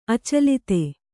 ♪ acalite